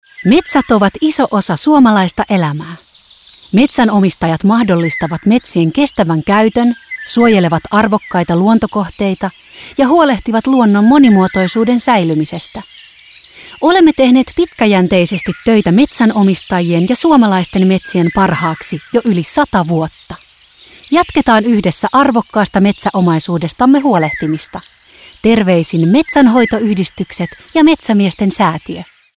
Mainokset kuuluvat kaupallisilla radiokanavilla kautta Suomen 13.10. alkaen viikoilla 41–42 ja 44–45.